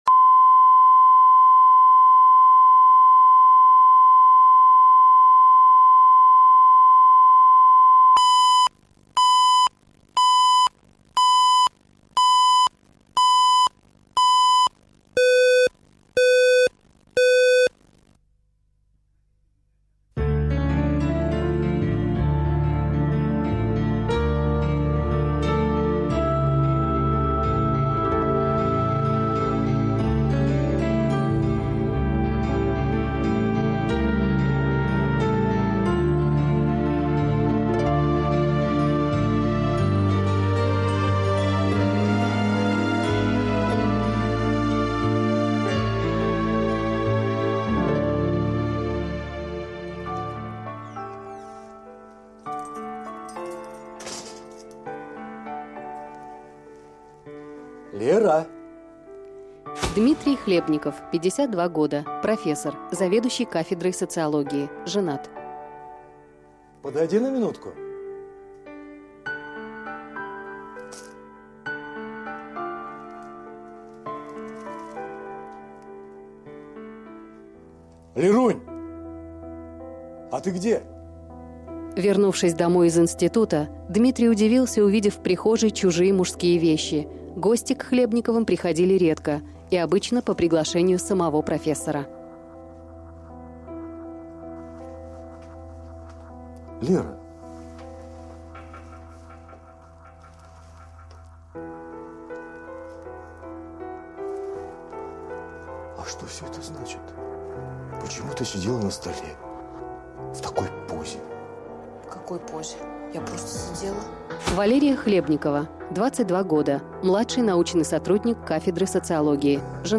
Аудиокнига Жена профессора | Библиотека аудиокниг